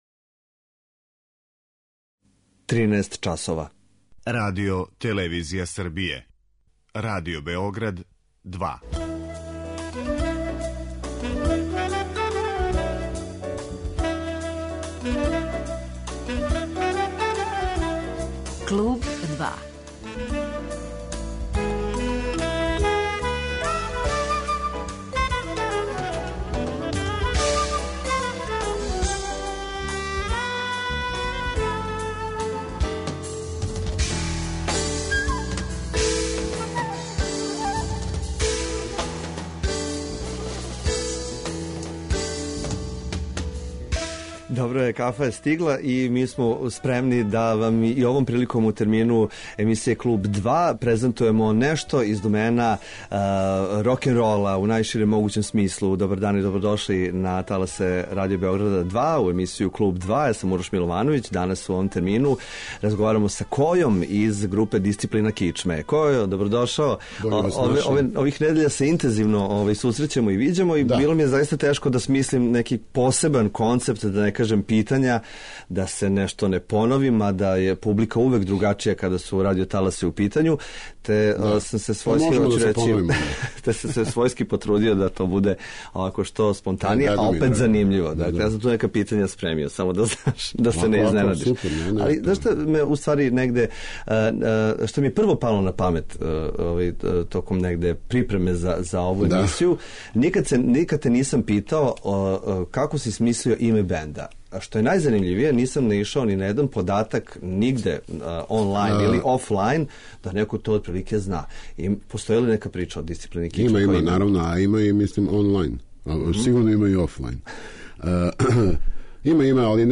Гост: Душан Којић Која